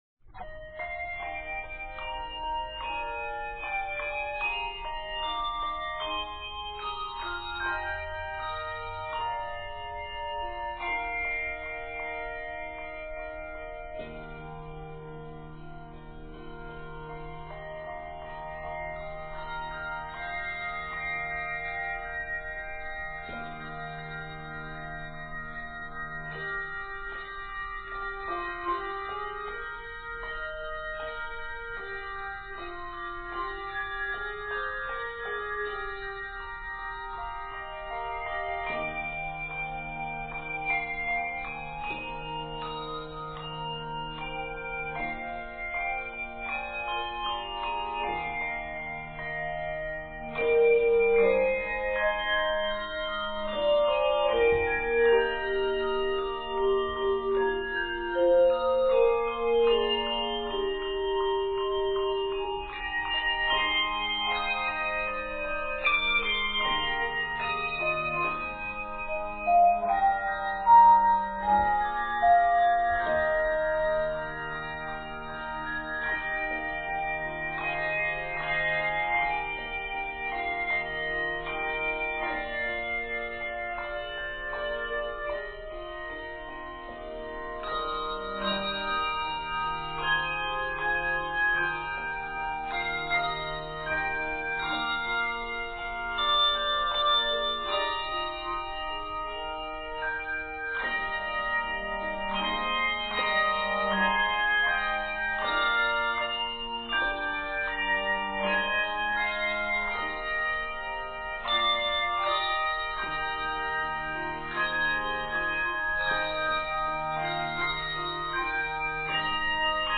Measures total 81 with a separate 3-4 or 5 octave ending.